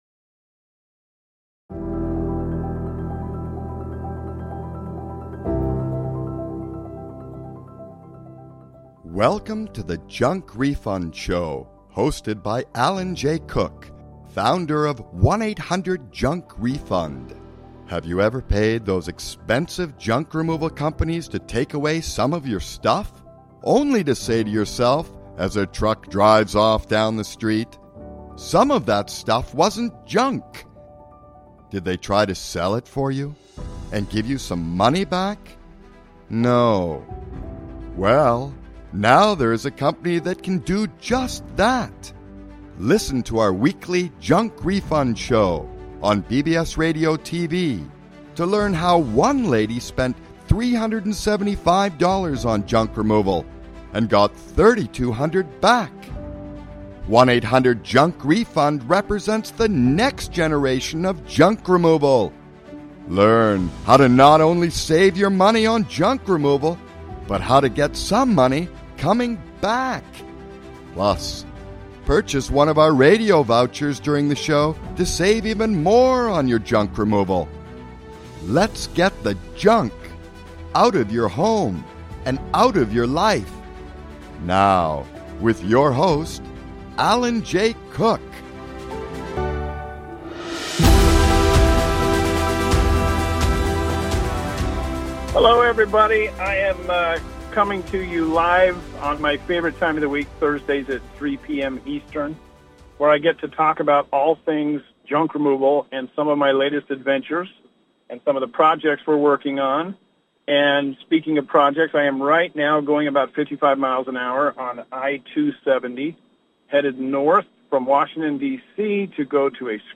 Interview takes place while on I-270 heading north from Washington D.C., on the way to a Scrap Metal Yard and then the county dump.